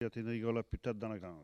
Localisation Sallertaine
Enquête Arexcpo en Vendée
Catégorie Locution